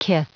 Prononciation du mot kith en anglais (fichier audio)
Prononciation du mot : kith